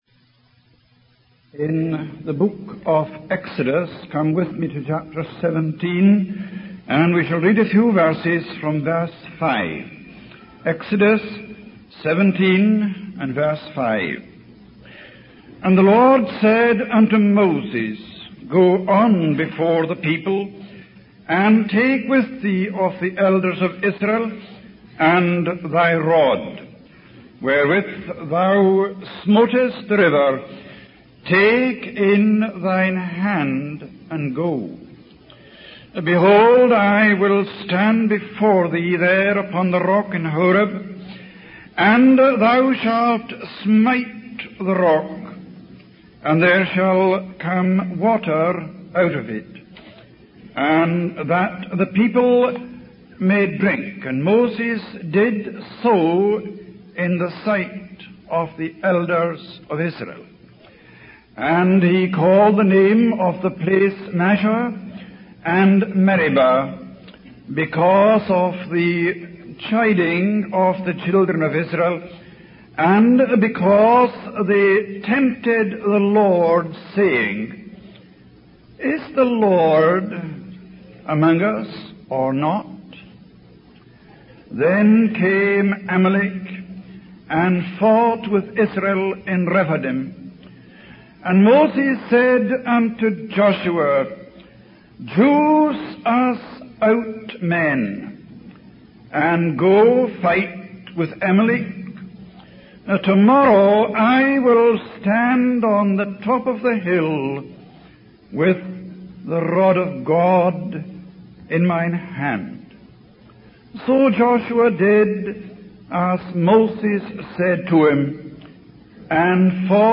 In this sermon, the speaker reflects on a young man's testimony about his experience with sanctification.